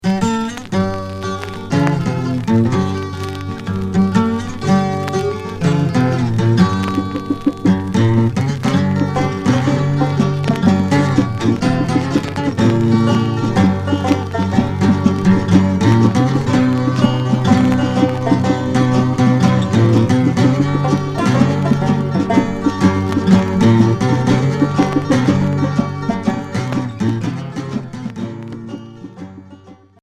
Folk religieux